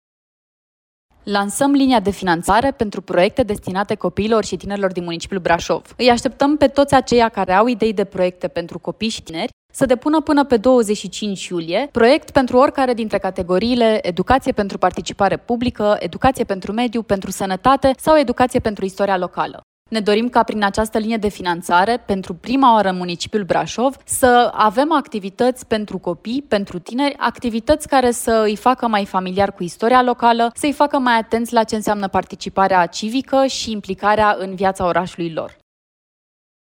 Conform Ghidului de finanțare aprobat de Consiliu Local, proiectele sprijinite în cadrul acestui program vor trebui să contribuie la creșterea participării civice a tinerilor, la creșterea calității vieții copiilor și tinerilor din Brașov, la identificarea nevoilor de politici publice locale în domeniul sănătății copiilor și tinerilor și la creșterea sentimentului de apartenență la comunitatea Brașovului. Viceprimarul Brașovului Flavia Boghiu.